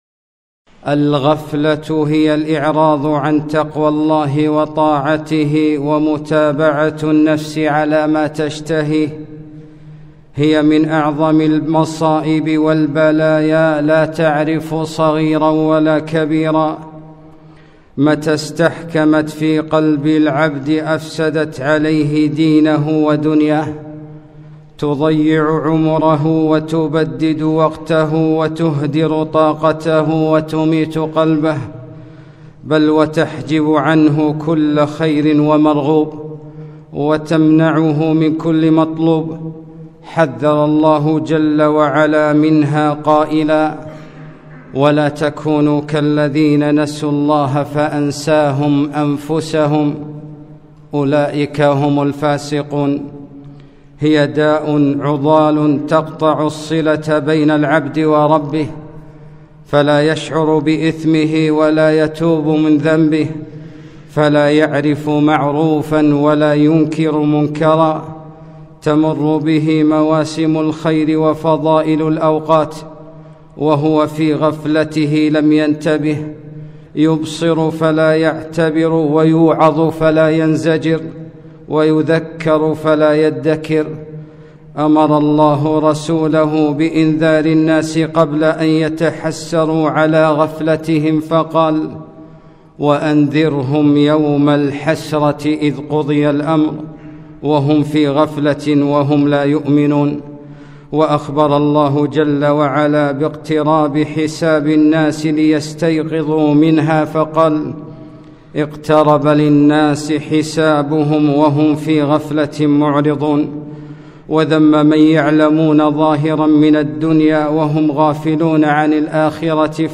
خطبة - الدّاء العضال